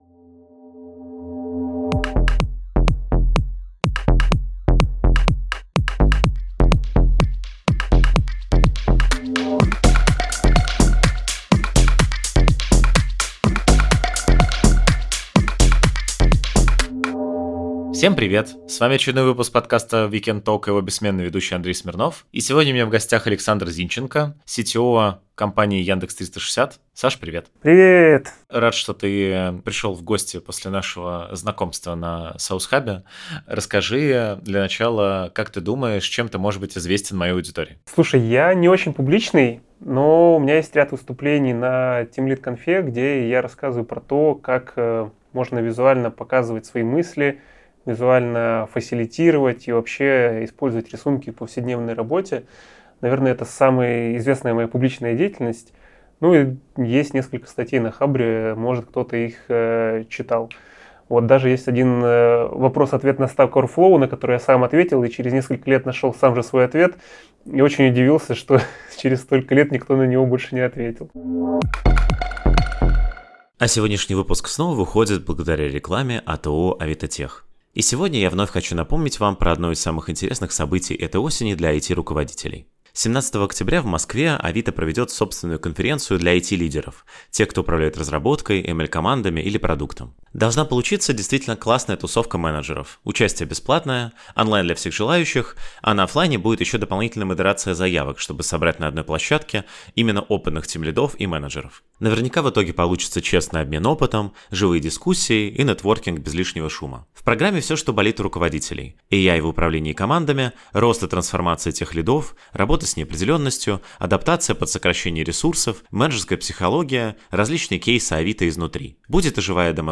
Самые честные интервью с яркими представителями IT-сферы. Разговоры по душам — про карьерный путь и жизнь вне работы, ошибки и успехи.